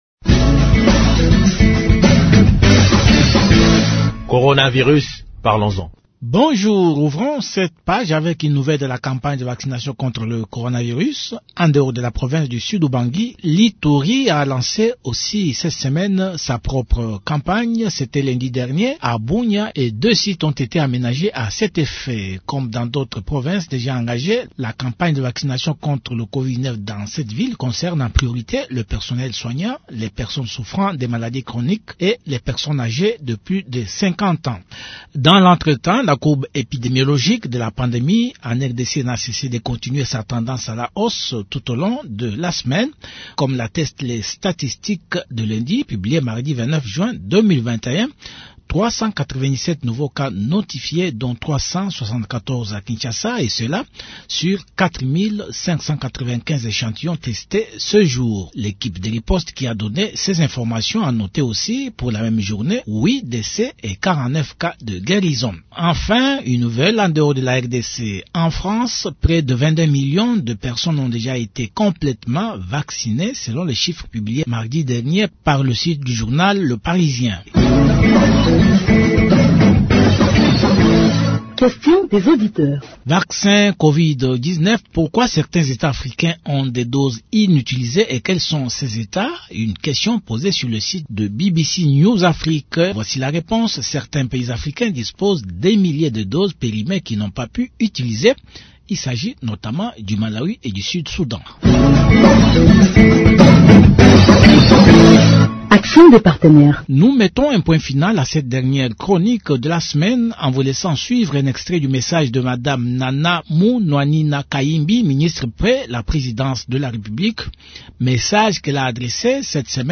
Un extrait du message de Mme Nana Manuanina Kihimbi, ministre près la présidence de la République, adressé aux élèves du Collège Saint Théophile, en rapport avec la lutte contre la 3e vague du Covid-19.